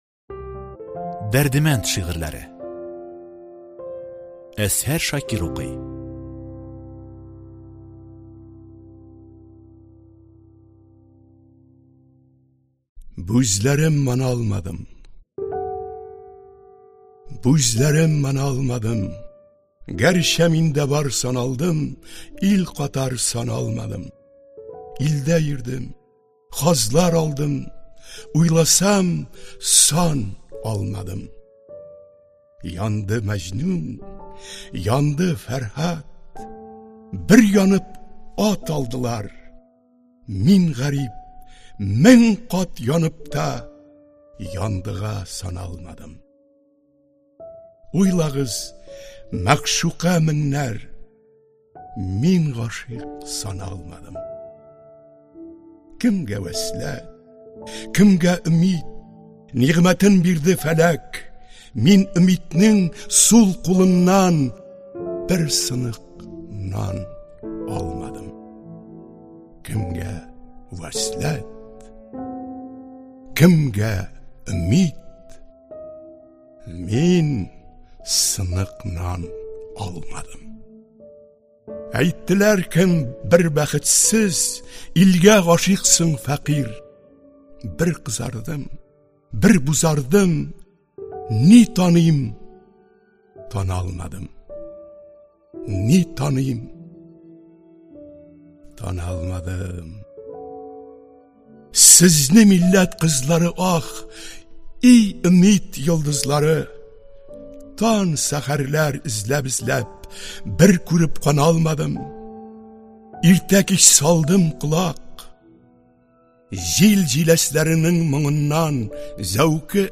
Аудиокнига Шигырьләр | Библиотека аудиокниг